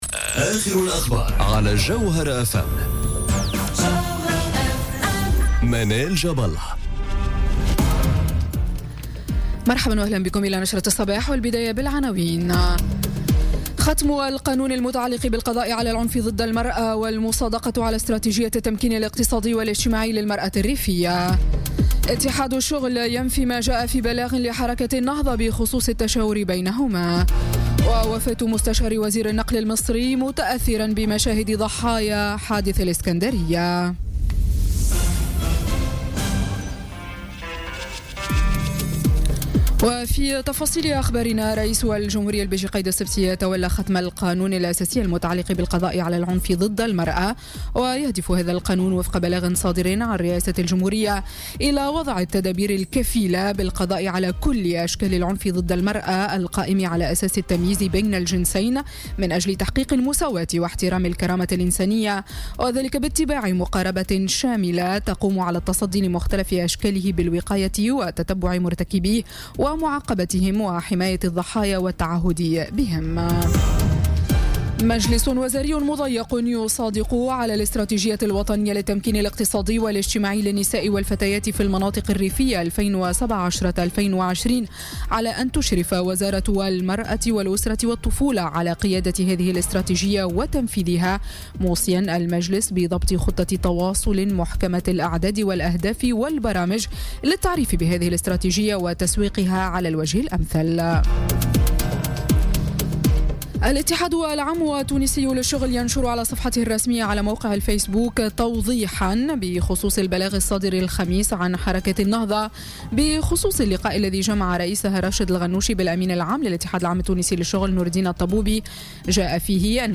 نشرة أخبار السابعة صباحا ليوم السبت 12 أوت 2017